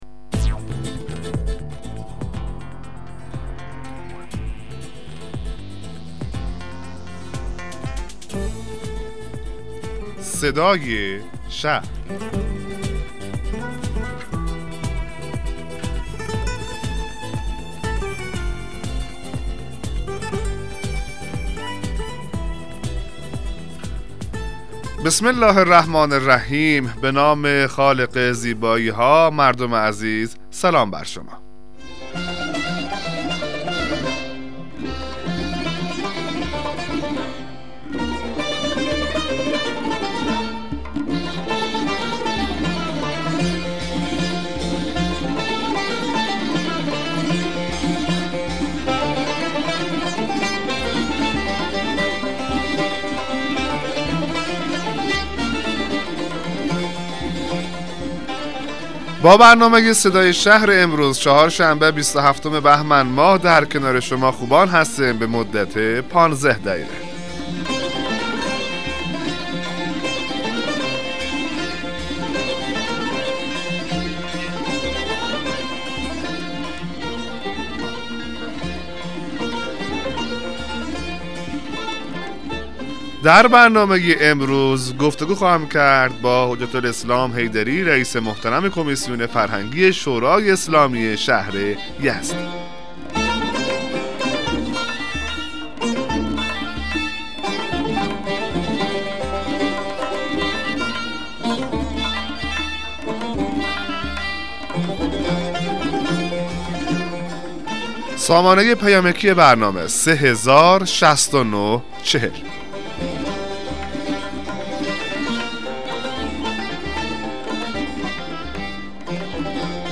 مصاحبه رادیویی برنامه صدای شهر با حضور سید محمد ناصر حیدری رییس کمیسیون فرهنگی ، اجتماعی و ورزشی شورای اسلامی شهر یزد